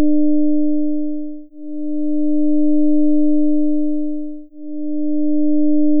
実部(右イヤホン)にはcos(2πt/6)をかけてAM変調し
実部：cos(2πt/6)×(0.220+0.255*cos2π×300t+0.487cos2π×600t+0.0332*cos2π×900t)